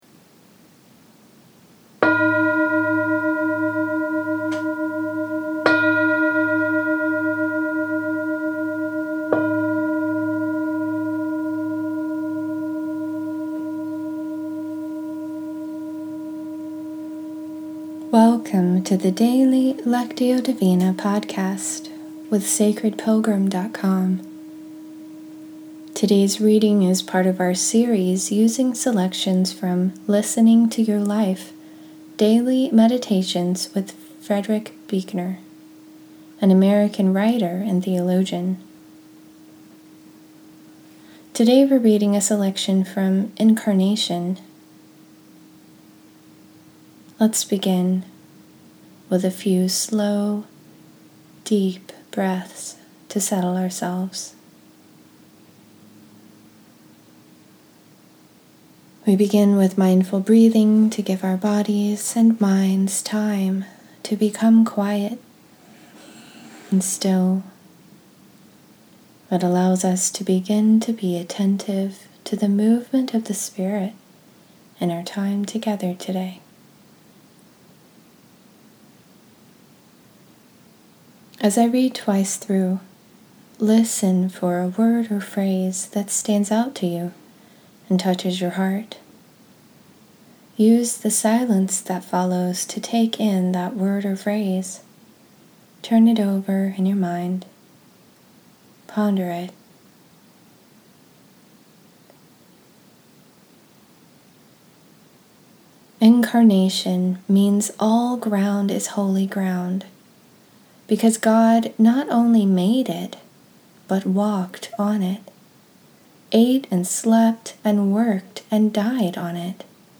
In this episode, we’re continuing our series using selections from Listening to Your Life: Daily Meditations with Frederick Buechner, an American writer and theologian. Today we’re reading a selection from “Incarnation.”